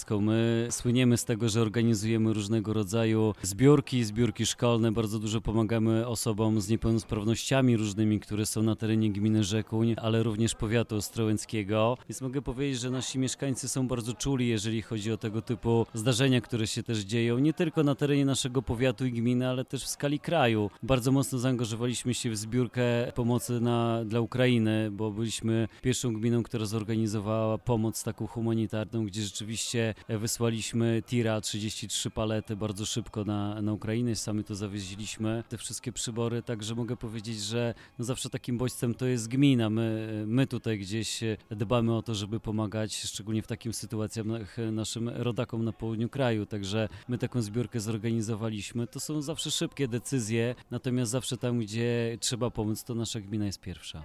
Jak mówi wójt – Bartosz Podolak – mieszkańcy gminy słyną z wielkich serc.